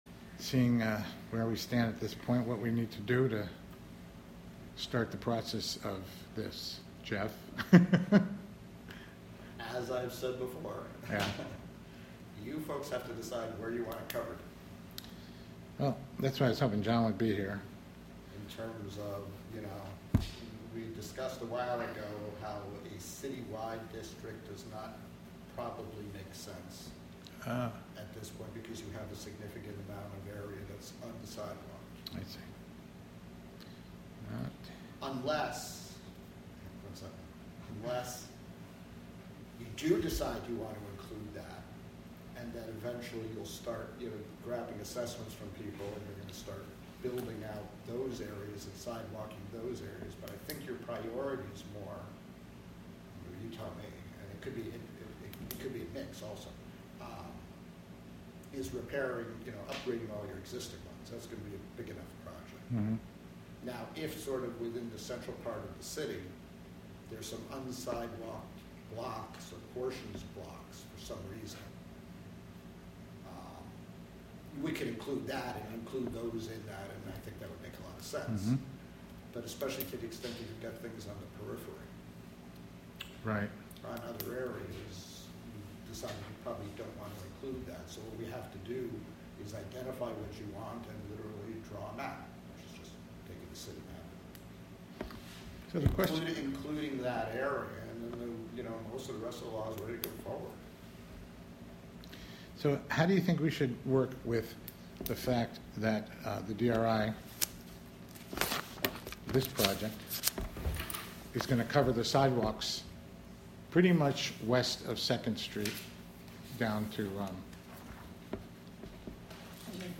Live from the City of Hudson: sidewalk Committee (Audio)